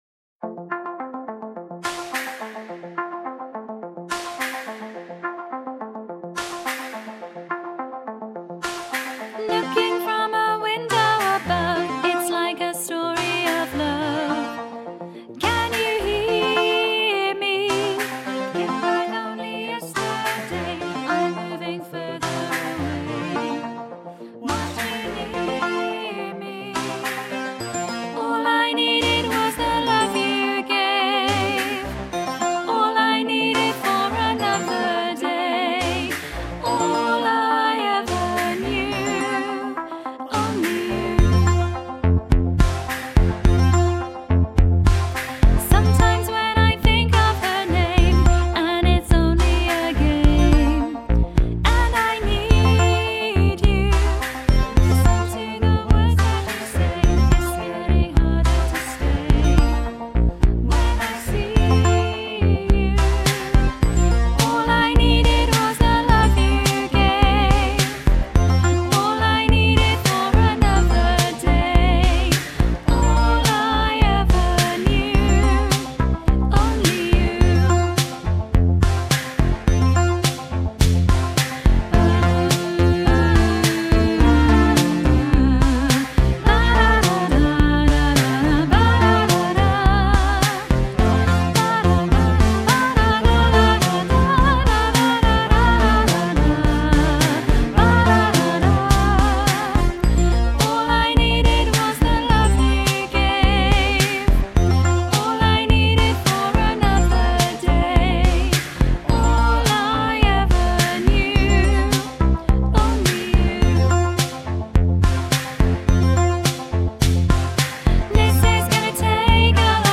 1-only-you-voice-1-high-half-mix.mp3